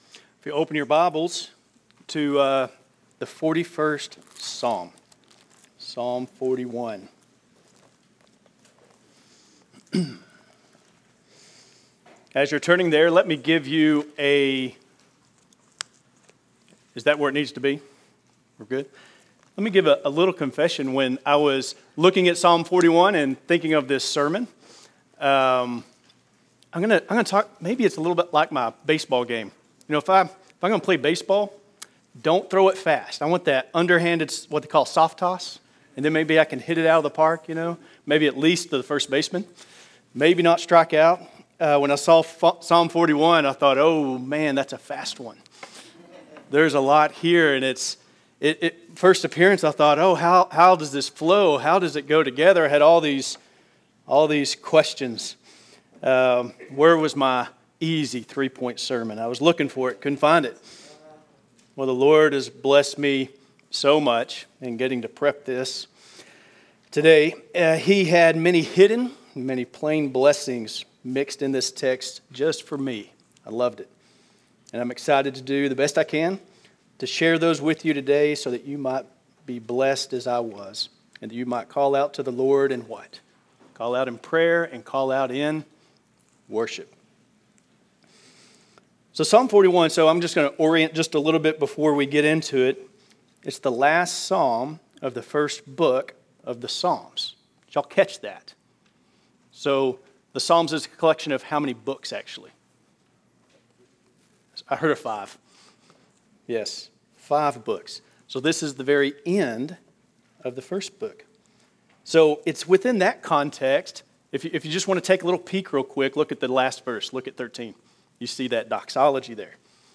Sermons
Sunday Sermons